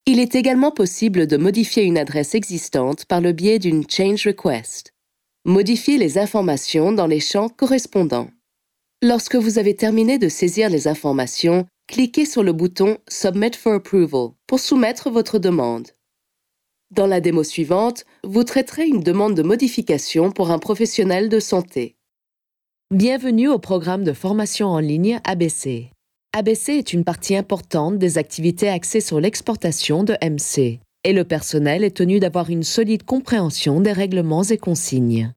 • 2955 | Francés – Europeo
Corporativa
2955-french_european-female-corporate.mp3